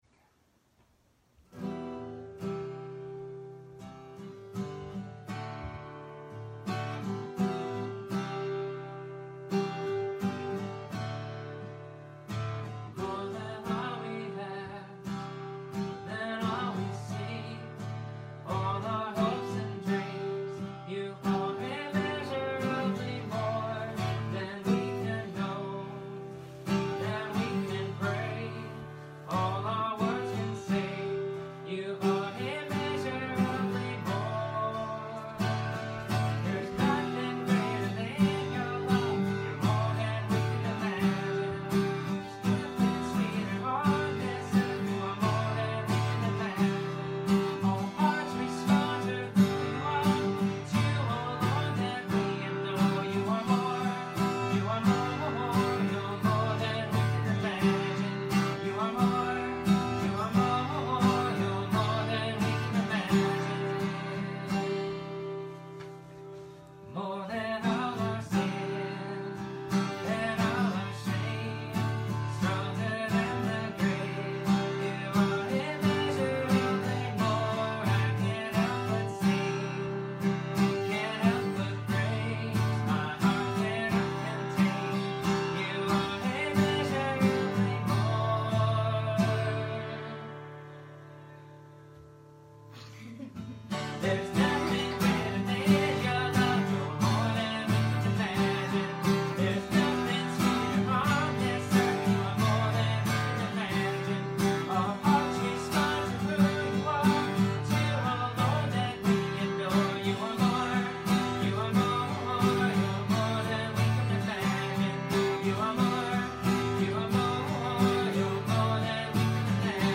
Matthew 1:1-24 Service Type: Sunday Morning Sermon Downloads Youversion Link Message Notes Kid's Notes 3 Provisions for the journey